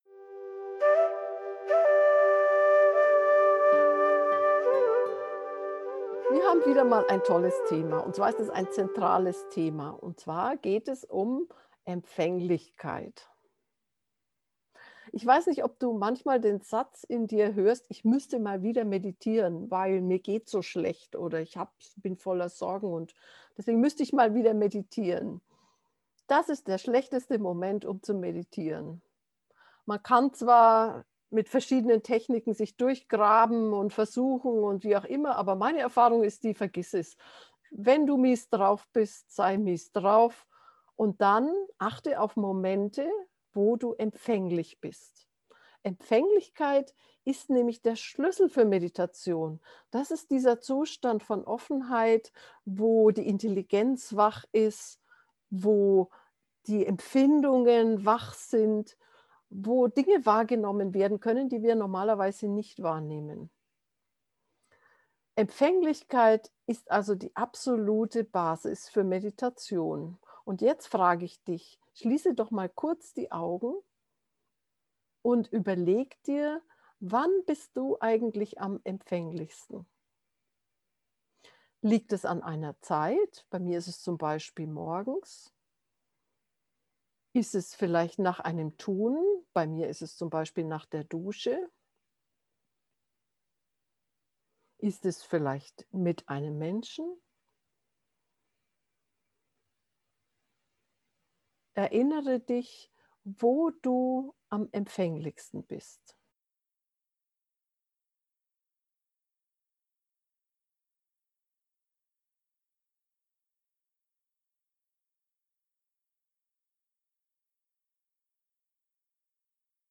Aus deinem Zentrum im Bauch hörst du auf Töne und lässt sie in dich fallen, so übst du Empfänglichkeit.
empfaenglichkeit-ueben-gefuehrte-meditation